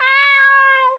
Cat.ogg